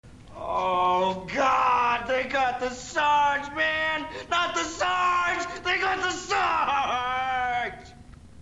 crybaby.
blubblubblubblubblub.mp3